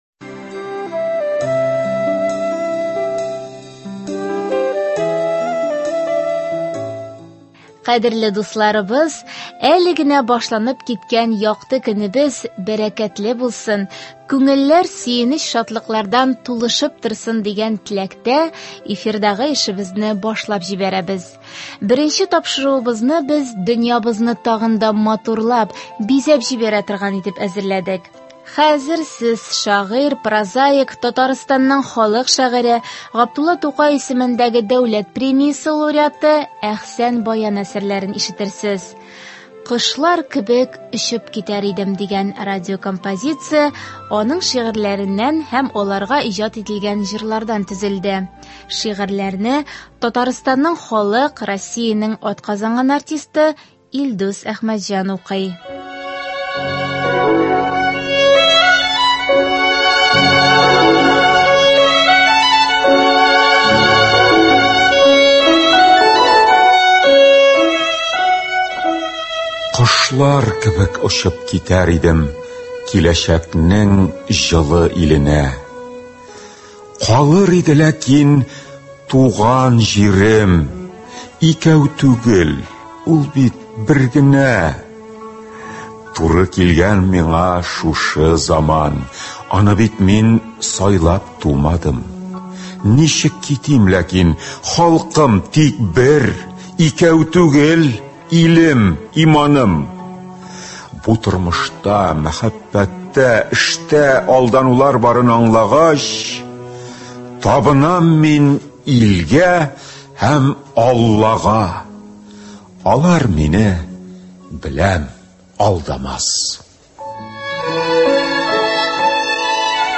Әдәби-музыкаль композиция (20.07.24)